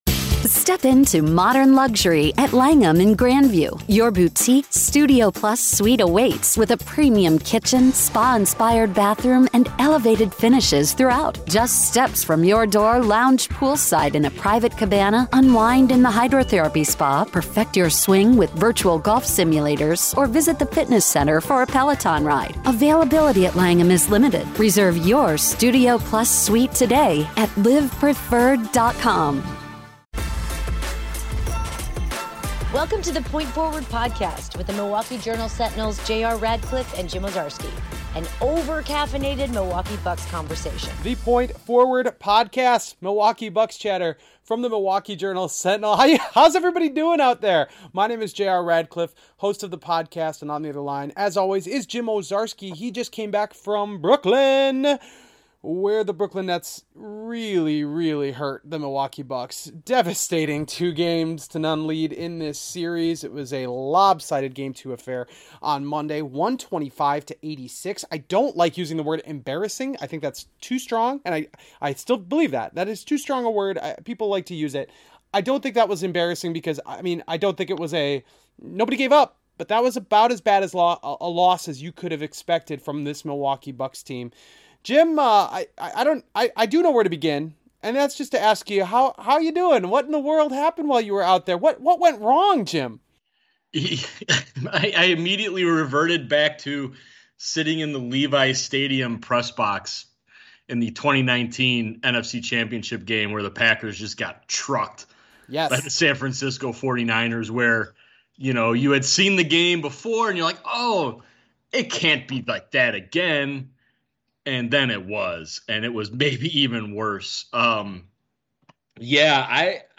Music intro